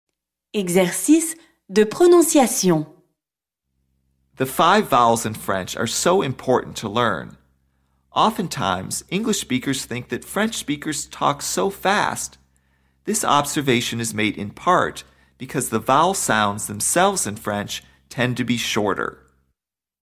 PRONONCIATION
Unlike English vowel sounds, which tend to drag on, French vowel sounds are short and crisp.
a – This letter sounds like the “a” when you sing the notes “la-la-la.”
e – This letter sounds like the vowel sound in the English word “pearl.”